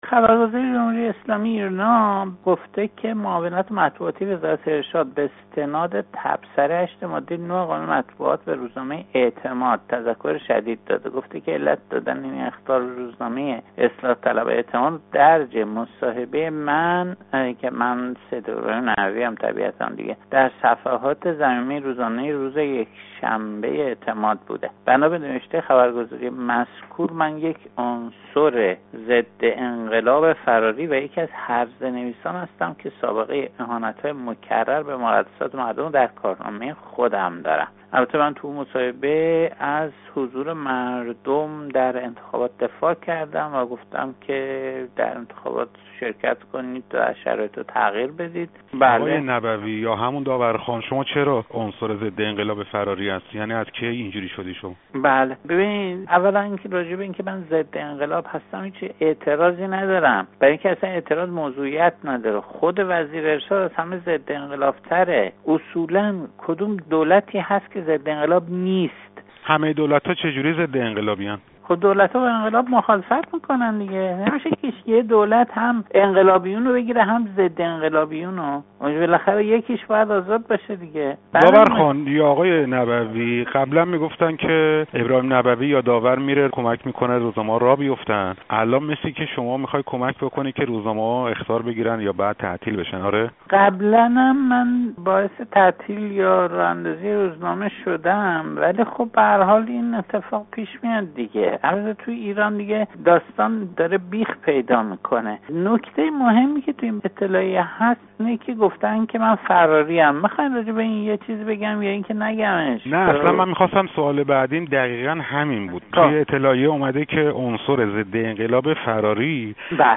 گفت و گو با ابراهيم نبوی روزنامه نگار و طنز نويس